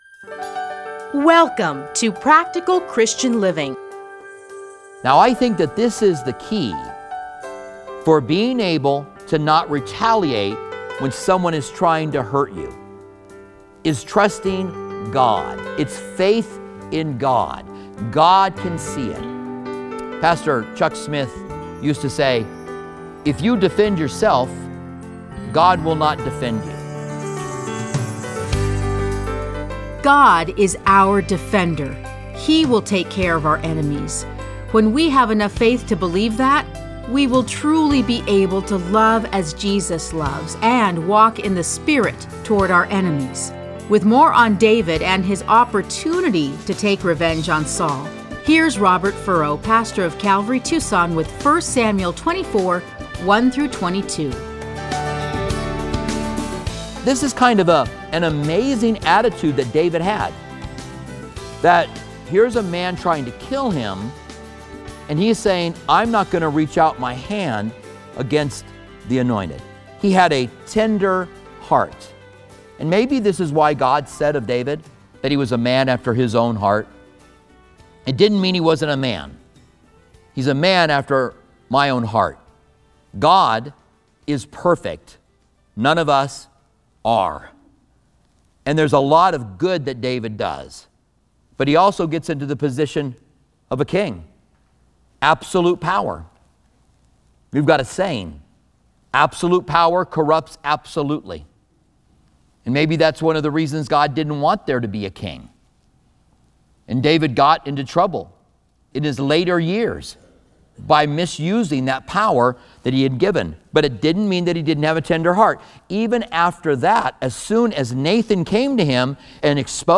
Listen to a teaching from 1 Samuel 24:1-22.